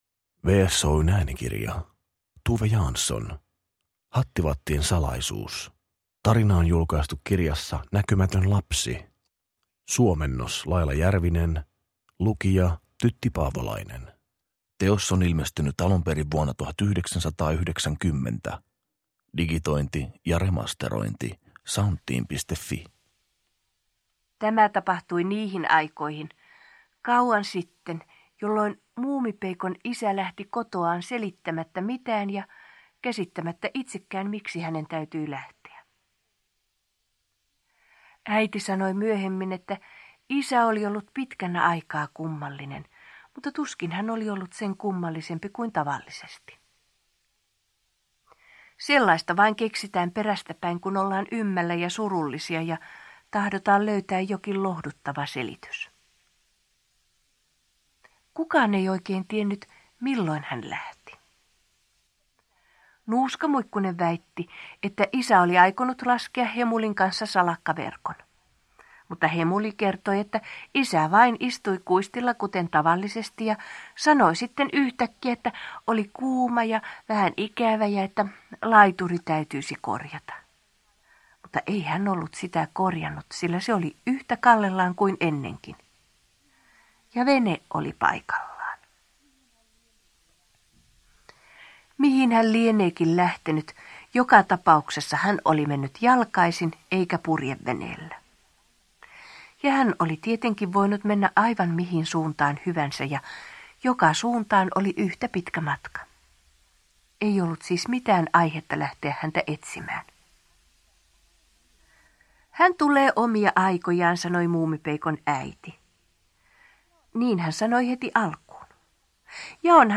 Hattivattien salaisuus – Ljudbok – Laddas ner